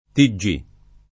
vai all'elenco alfabetico delle voci ingrandisci il carattere 100% rimpicciolisci il carattere stampa invia tramite posta elettronica codividi su Facebook TG [ ti JJ&+ ] o T.G. [ id. ] n. pr. m. — sigla di Tele-Giornale — scritto anche in tutte lettere tiggì [ id. ] (non tigì )